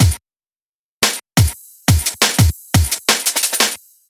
全面崩溃的DnB节拍1
描述：一个完整的鼓和贝斯的霹雳舞，可以把事情混为一谈。175 BPM。
Tag: 175 bpm Drum And Bass Loops Drum Loops 708.87 KB wav Key : Unknown